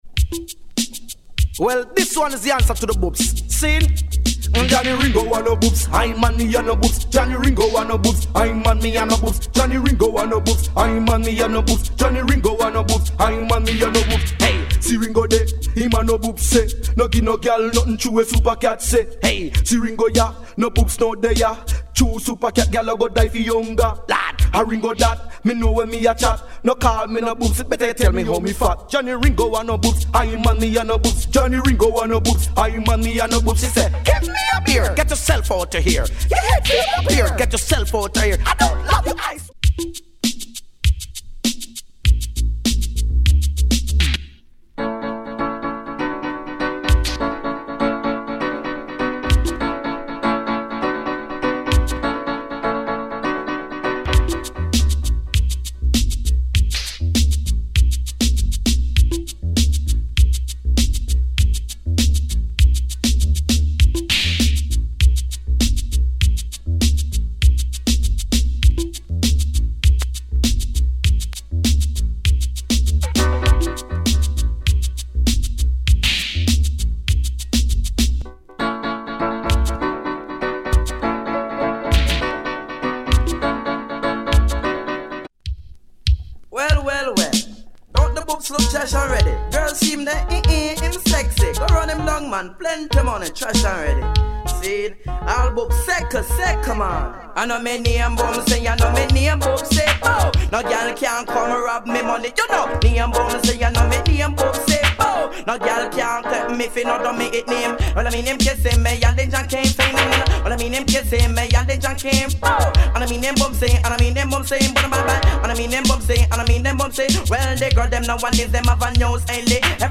We record our sound files with no EQ is added.